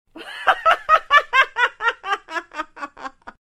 cackle.wav